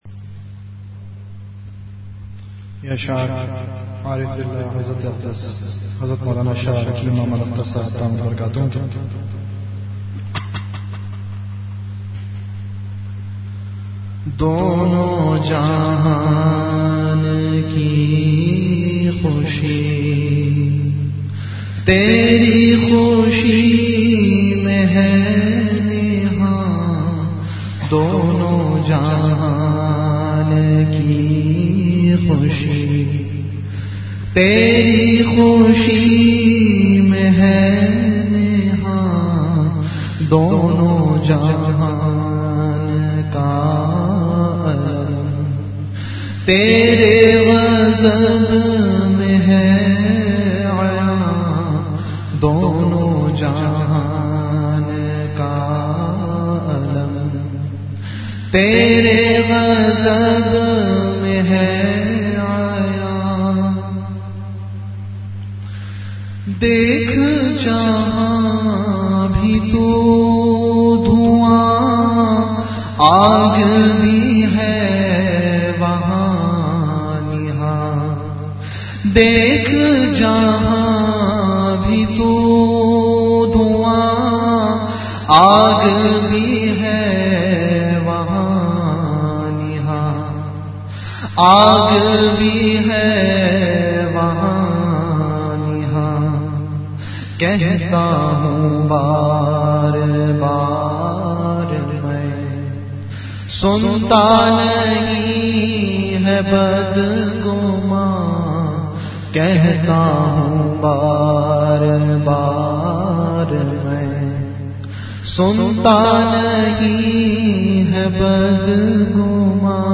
Delivered at Home.
Venue Home Event / Time After Isha Prayer